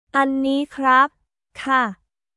アンニー クラップ／カー